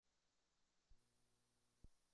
rueda-de-prensa-impacto-en-las-remesas-mexicanas-por-el-endurecimiento-de-la-politica-migratoria-en-estados-unidos.mp3